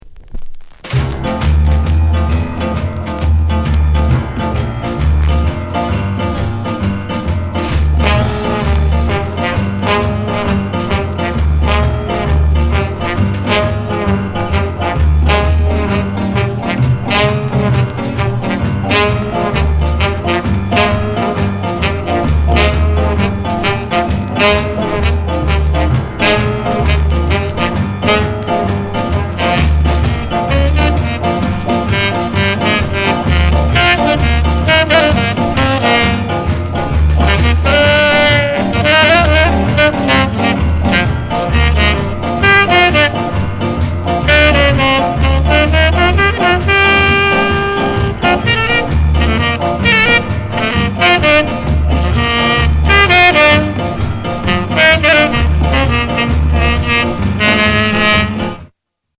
GREAT SKA INST